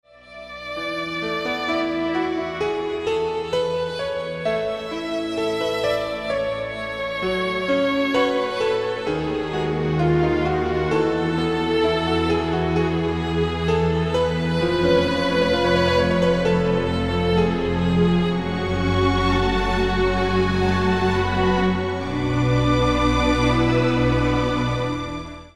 Muzyka o charakterze muzyki filmowej.